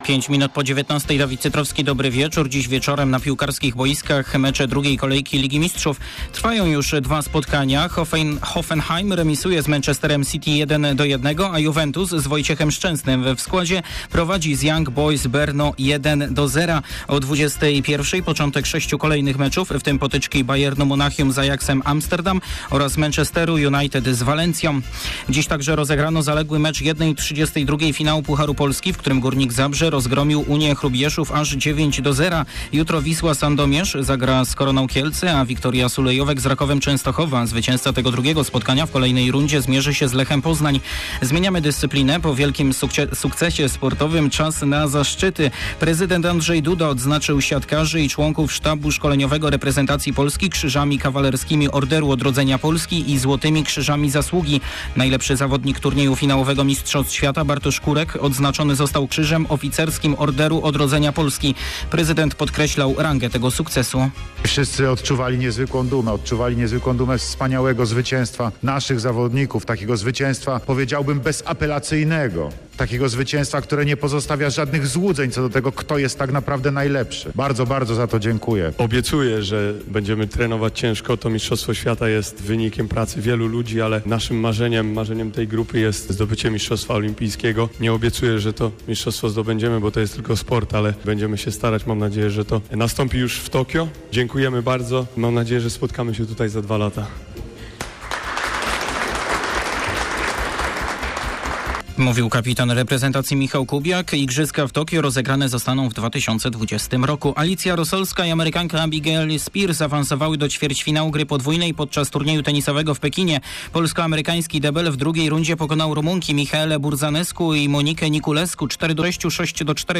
02.10 serwis sportowy godz. 19:05